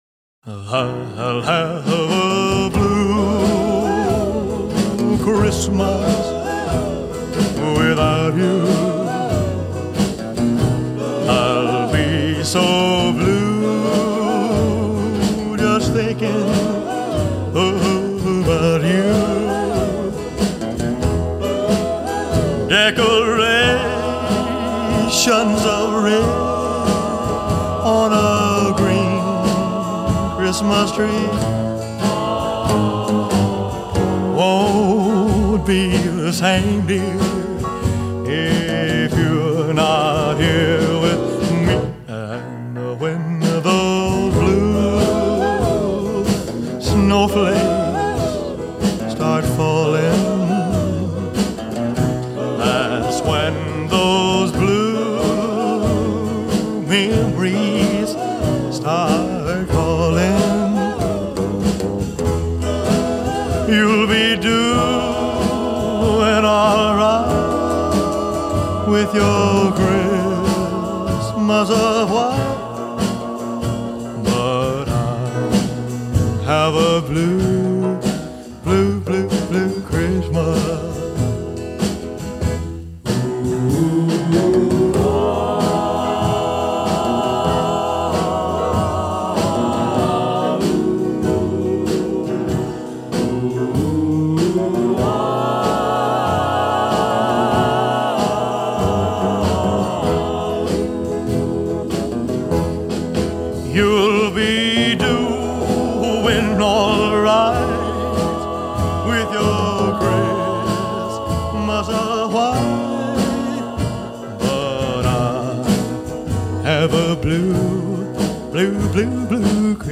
rock-and-roll holiday classic